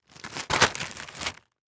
Plastic Bag Insert Sound
household
Plastic Bag Insert